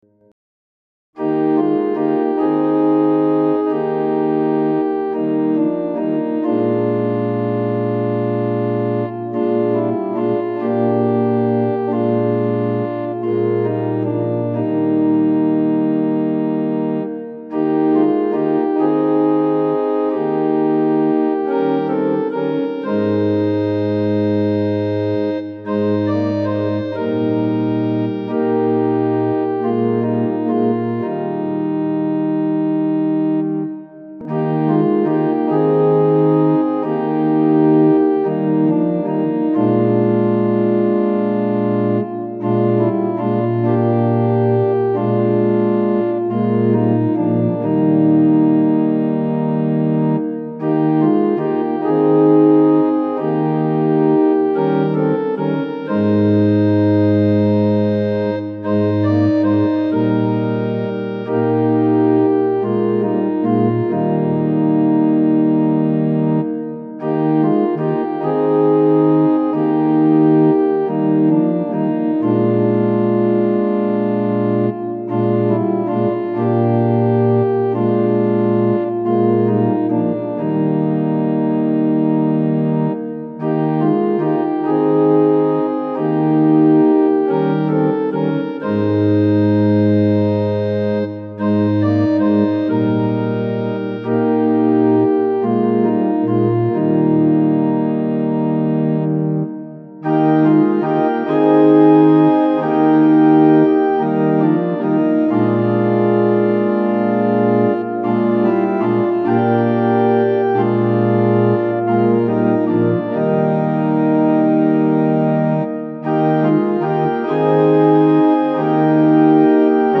Tonality = Es Pitch = 440 Temperament = Equal